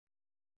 ♪ eḍedoṛe